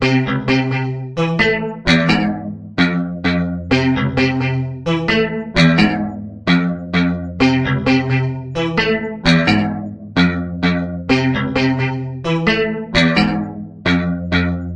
描述：舞蹈和电子音乐|放克
标签： 合成器 吉他
声道立体声